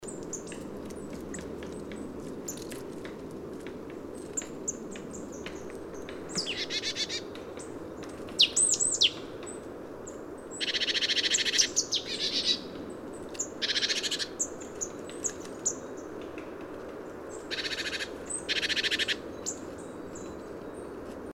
PFR07505, 130204, Marsh Tit Poecile palustris, 3, song, calls